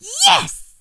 shelly_kill_02.wav